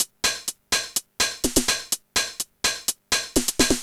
Index of /musicradar/retro-house-samples/Drum Loops
Beat 17 No Kick (125BPM).wav